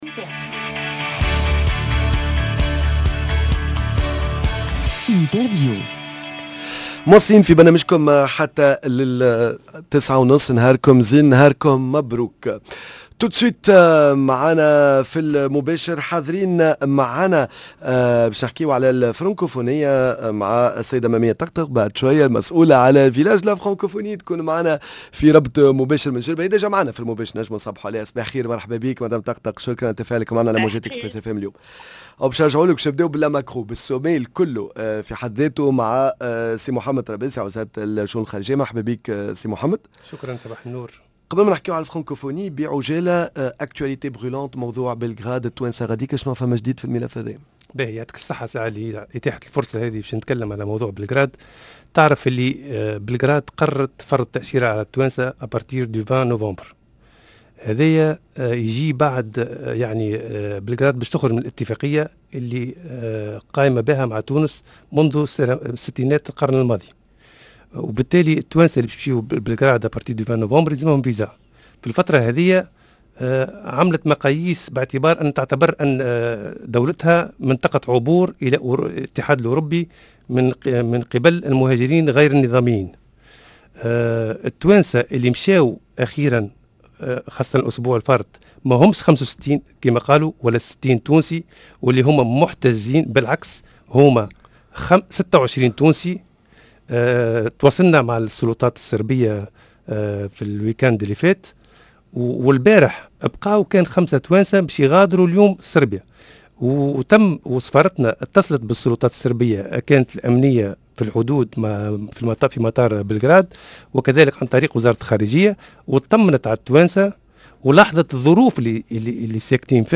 en direct de Djerba